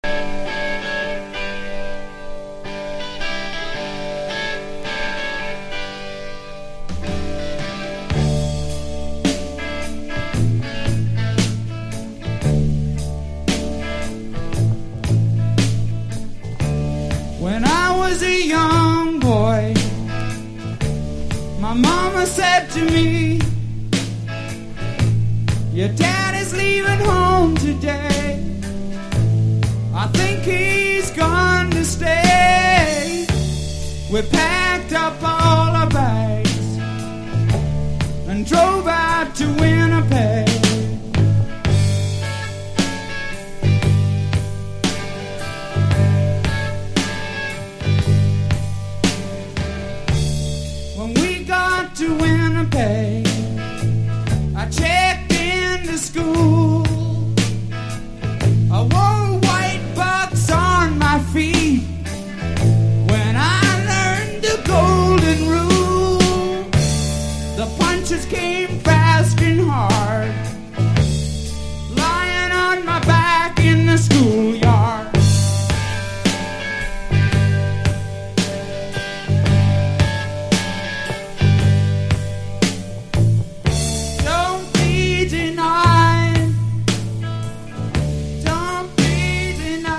1973年の全米ツアーの模様を収録。力強くパワフルな演奏を聴きつつ